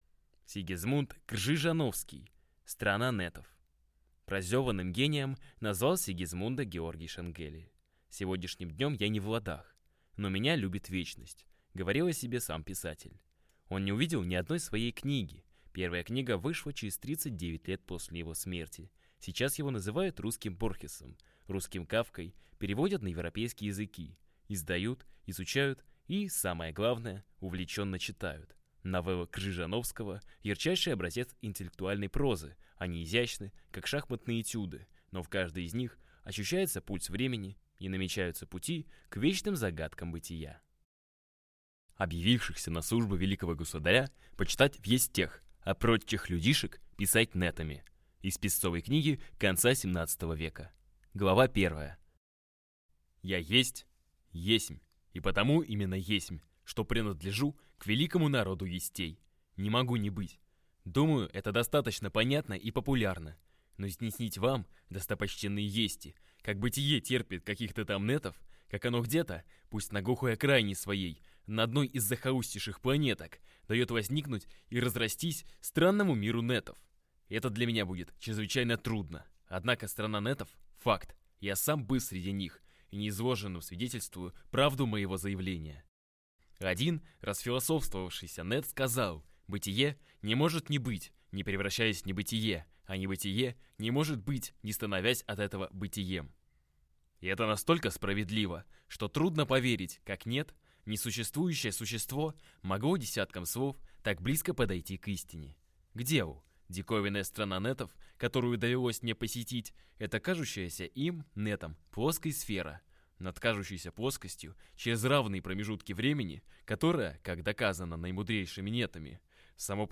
Аудиокнига Страна нетов | Библиотека аудиокниг